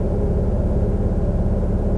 vehiclemisc.wav